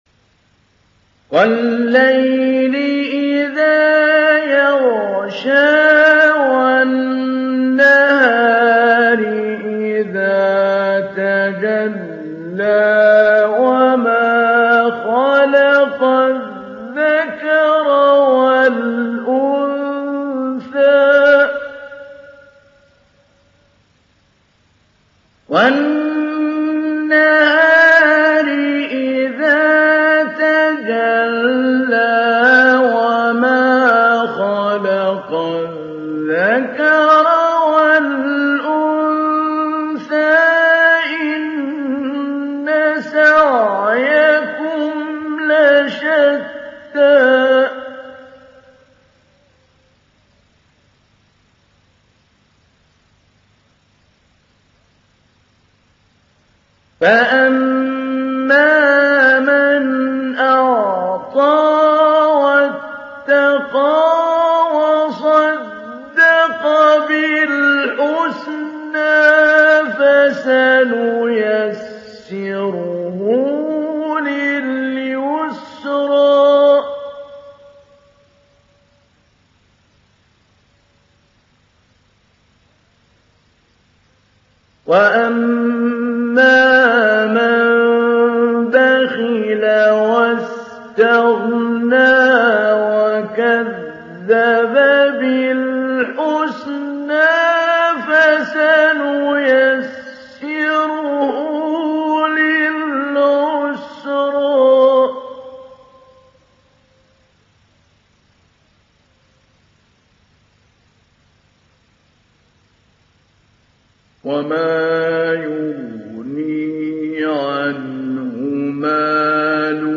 تحميل سورة الليل mp3 بصوت محمود علي البنا مجود برواية حفص عن عاصم, تحميل استماع القرآن الكريم على الجوال mp3 كاملا بروابط مباشرة وسريعة
تحميل سورة الليل محمود علي البنا مجود